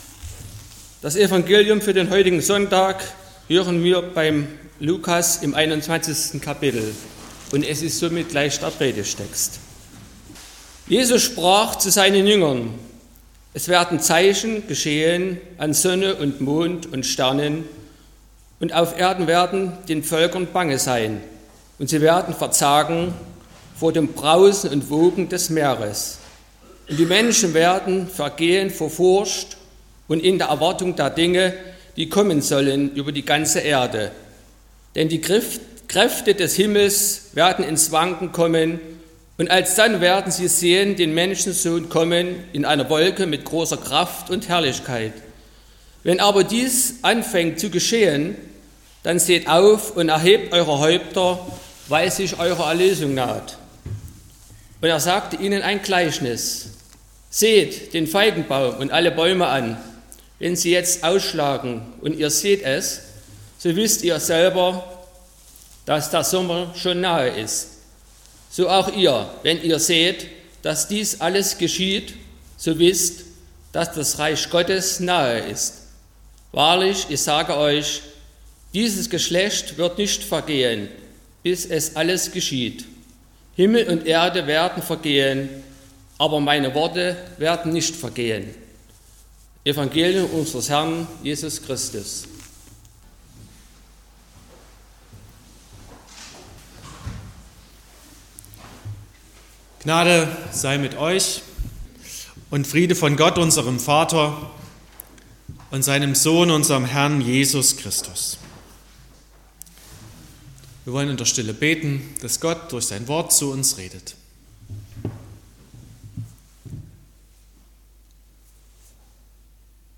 07.12.2025 – Gottesdienst
Predigt und Aufzeichnungen